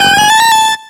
Cri de Porygon2 dans Pokémon X et Y.